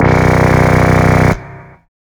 SYNTHBASS3-L.wav